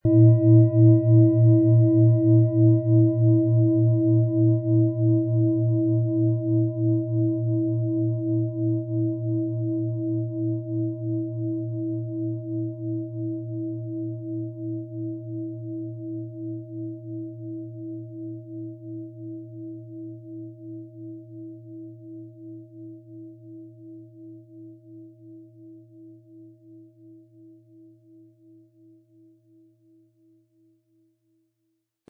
Planetenton 1
Planetenschale® Geborgen fühlen & Eigene Bedürfnisse leben mit Mond, Ø 25,1 cm inkl. Klöppel
Sanftes Anspielen wird aus Ihrer bestellten Klangschale mit dem beigelegten Klöppel feine Töne zaubern.
MaterialBronze